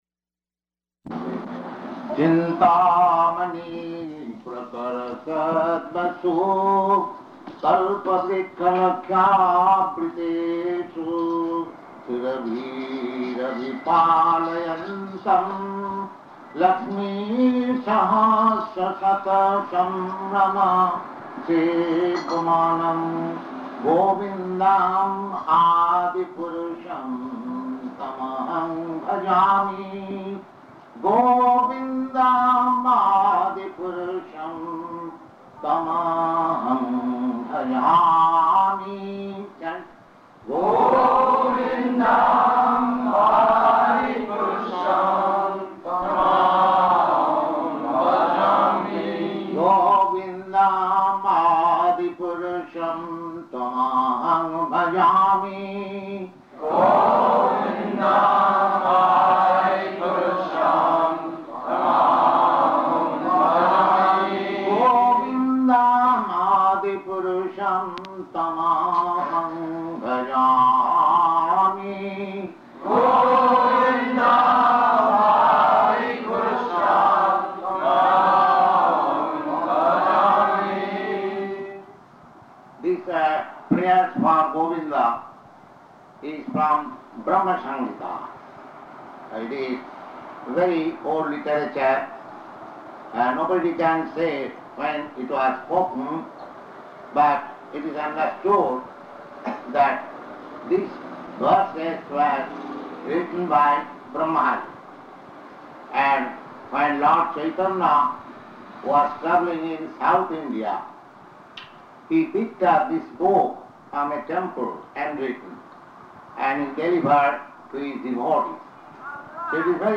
Location: San Francisco
Prabhupāda: [singing]